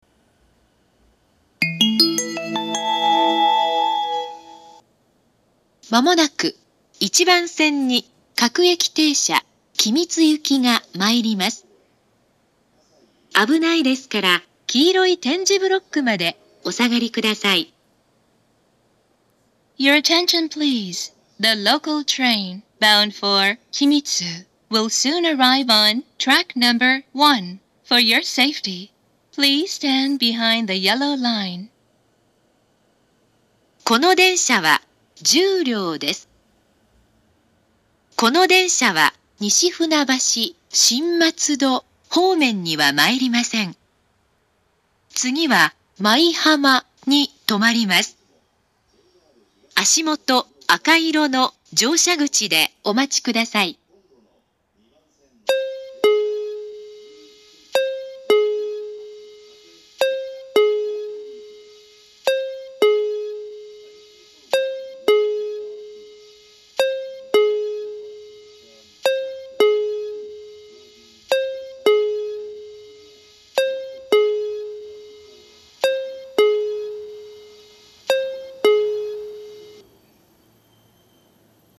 ２０１６年９月２５日には、自動放送がＡＴＯＳ型放送に更新されています。
１番線接近放送
１番線到着放送